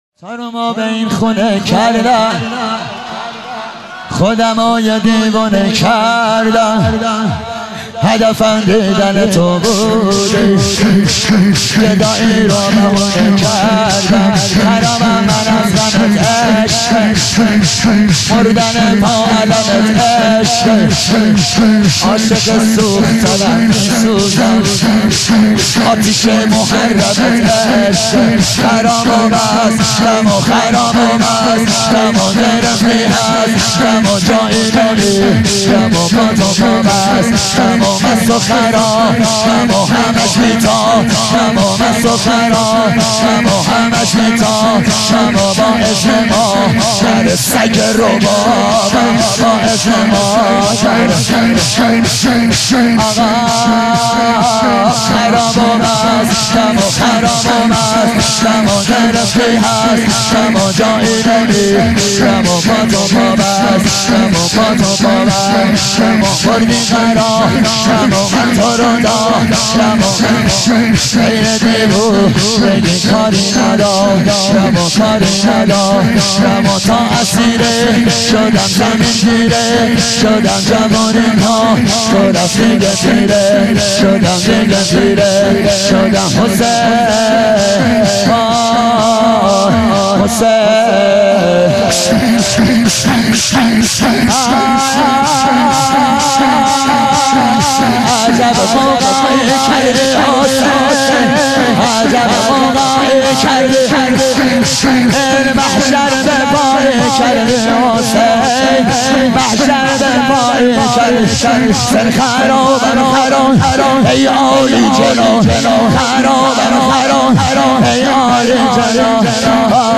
شب هفتم رمضان95